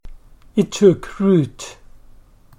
sound_loud_speaker It took root. /tʊk/ /rt/